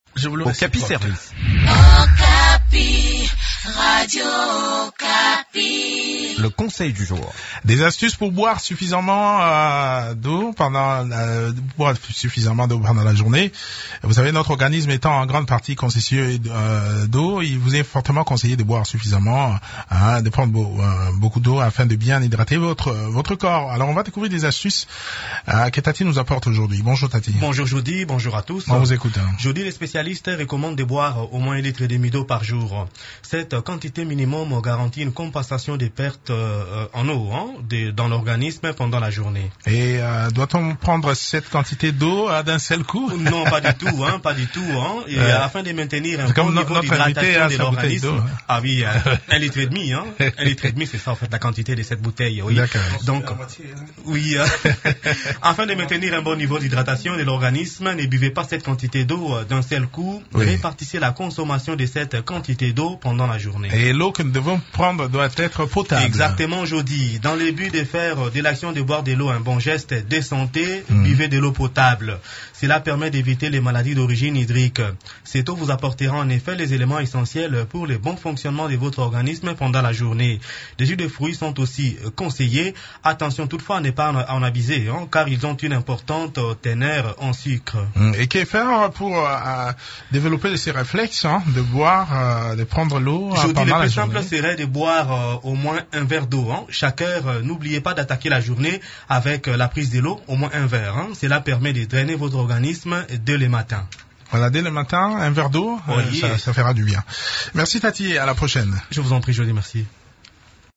Découvrez des astuces qui peuvent vous aider à boire suffisamment de l’eau pendant la journée dans cette chronique